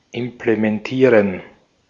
Ääntäminen
Synonyymit act out carry through Ääntäminen : IPA : /ˈɪm.plə.mənt/ US : IPA : [ˈɪm.plə.mənt] Tuntematon aksentti: IPA : /ˈɪmpləmɛnt/ IPA : /ˈɪm.plə.ˌmɛnt/ Lyhenteet ja supistumat imp.